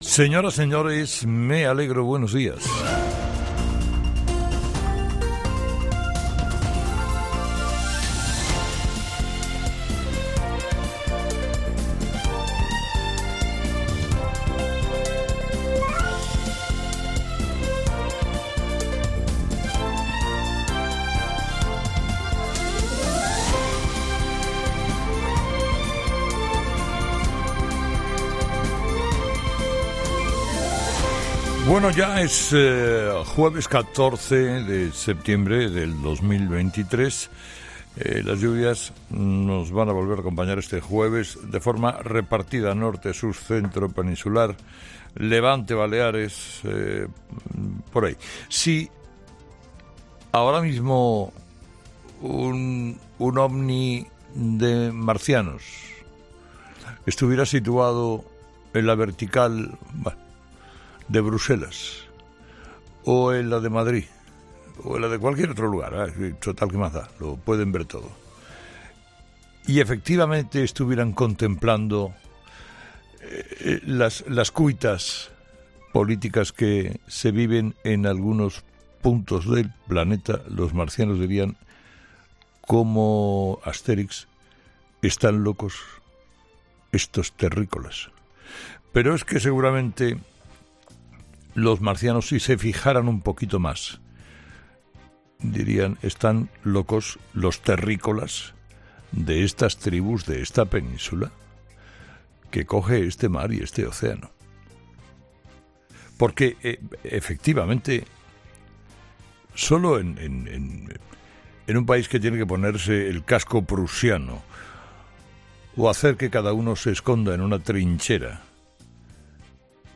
Carlos Herrera repasa los principales titulares que marcarán la actualidad de este jueves 14 de septiembre